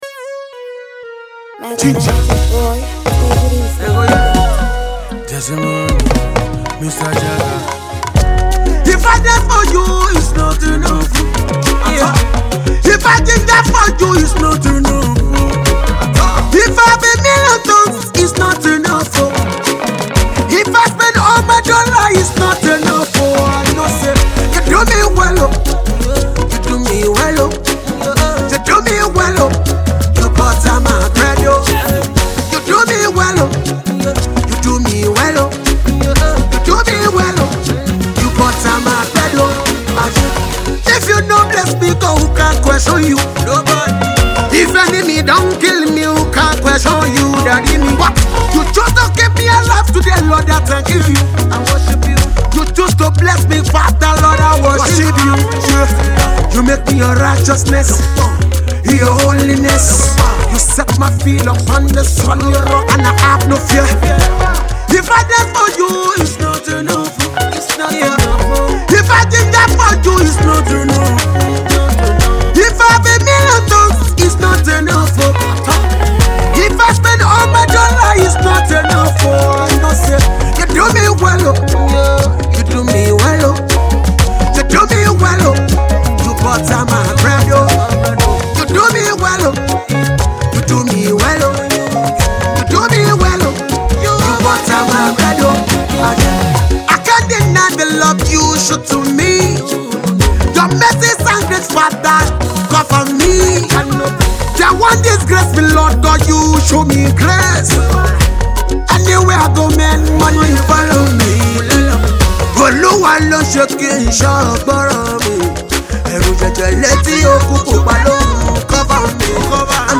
Energetic gospel artiste and Fuji-fusionist
a song of thanksgiving to God